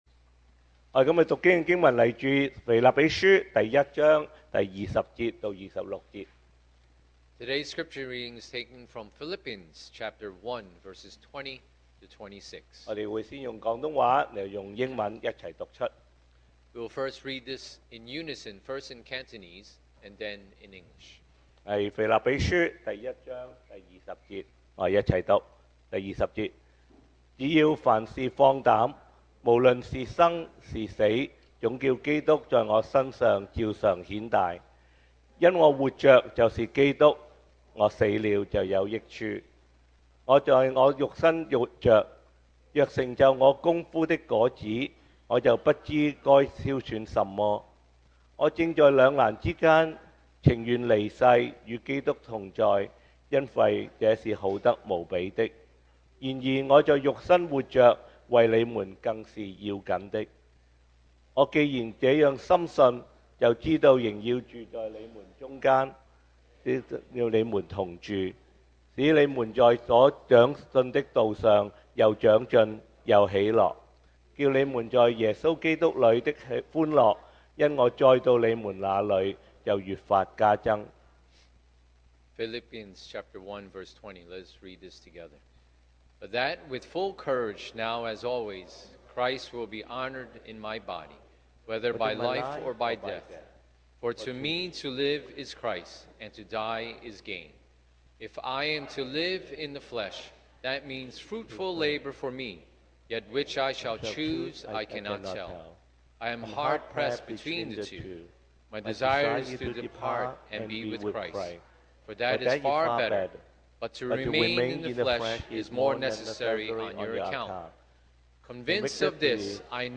sermon audios
Service Type: Sunday Morning